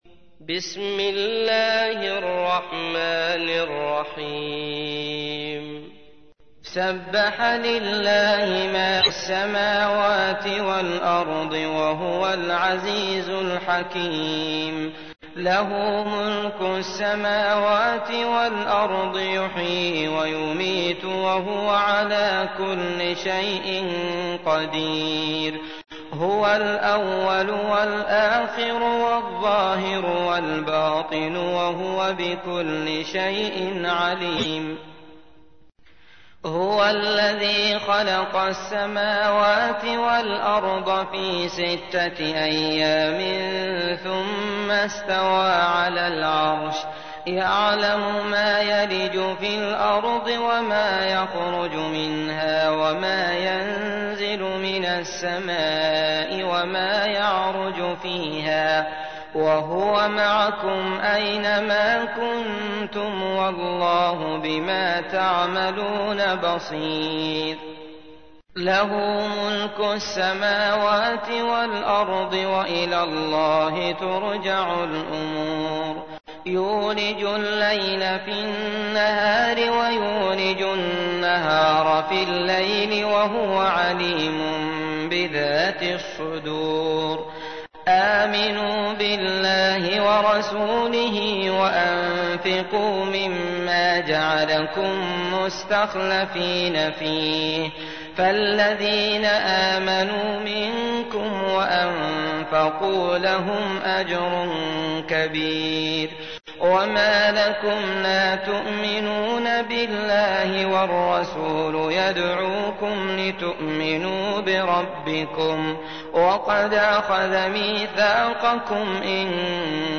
تحميل : 57. سورة الحديد / القارئ عبد الله المطرود / القرآن الكريم / موقع يا حسين